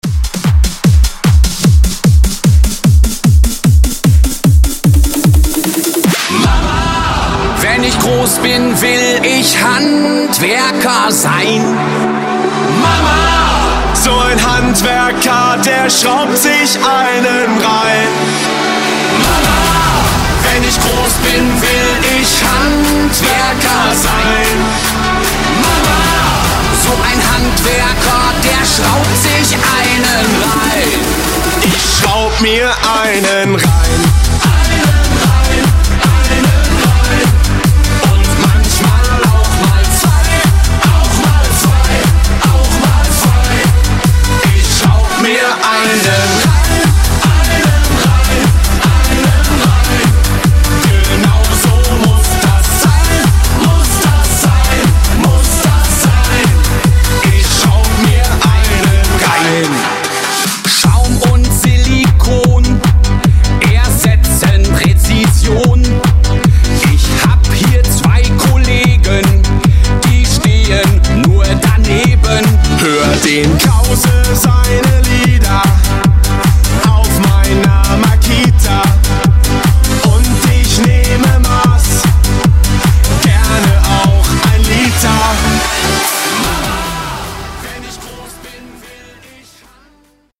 Genres: DANCE , RE-DRUM , TOP40
Clean BPM: 130 Time